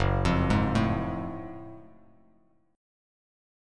F#mM9 chord